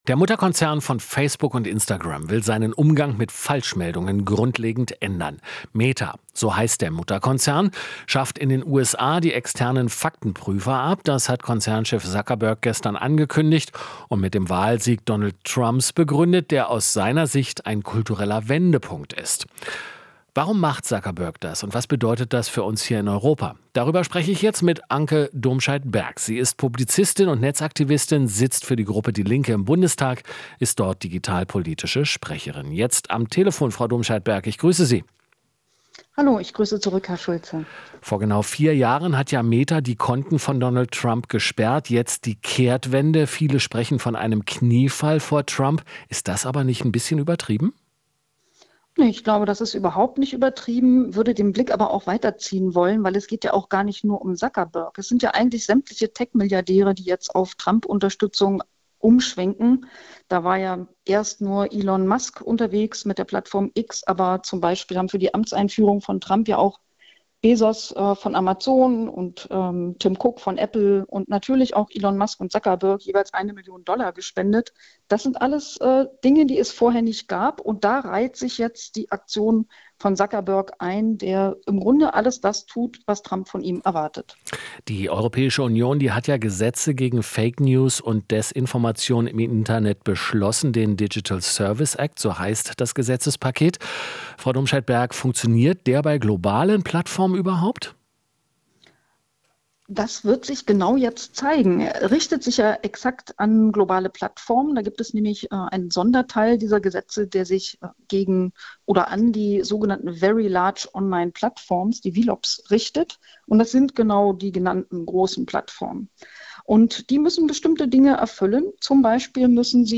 Interview - Domscheit-Berg (Linke) kritisiert Abschaffung von Faktenchecks bei Meta